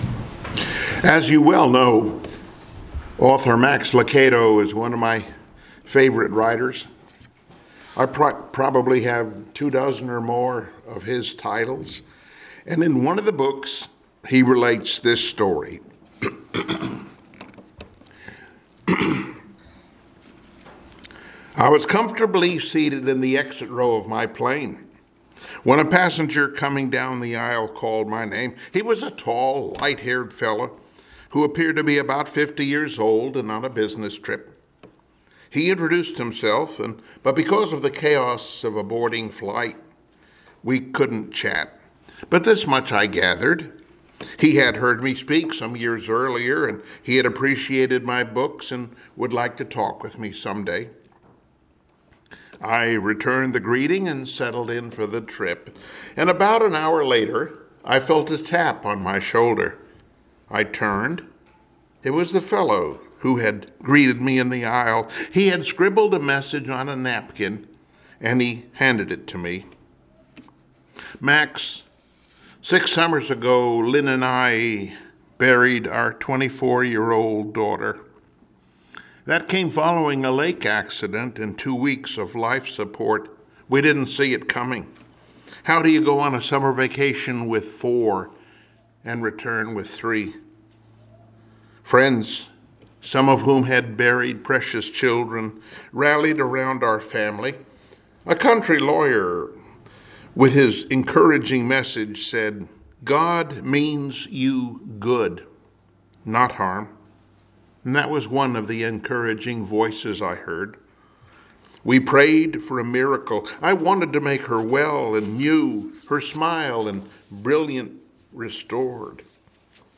Joshua 21:43-45 Service Type: Sunday Morning Worship Topics: Faith , Hope , Trusting God « “The God Drenched Mind” “God Fights For You” »